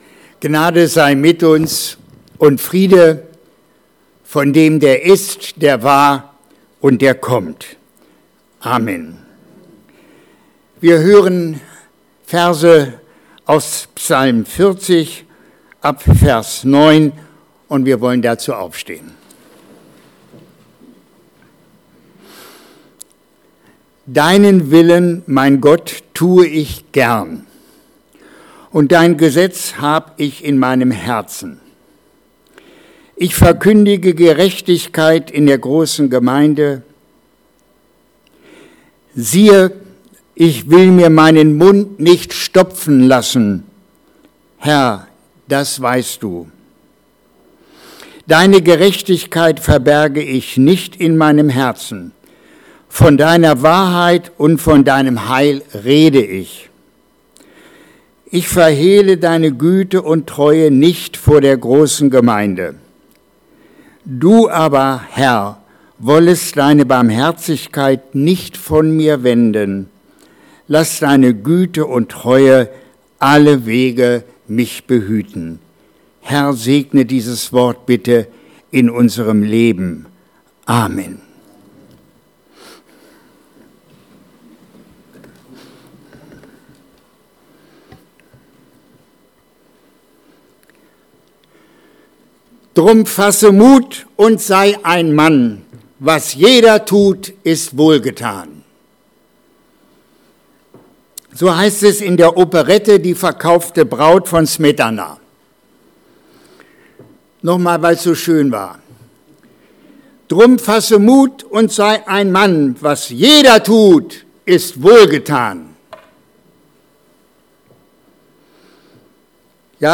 Dienstart: Gottesdienst
predigt-0403.mp3